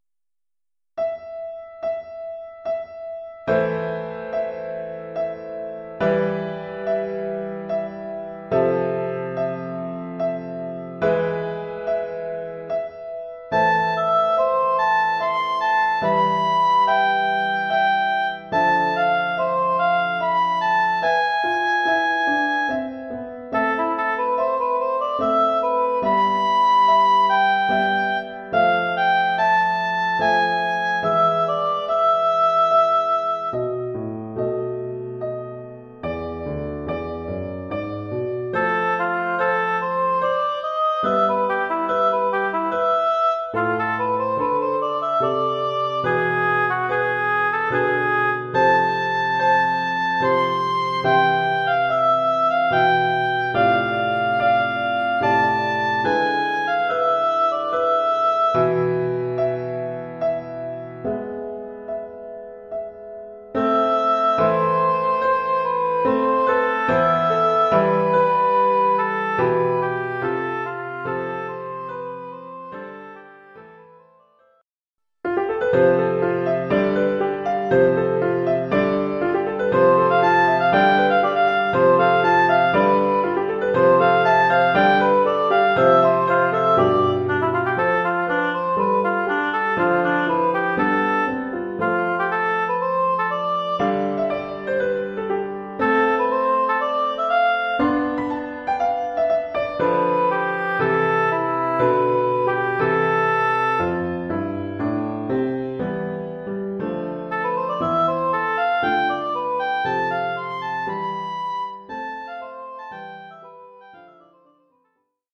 Oeuvre en deux mouvements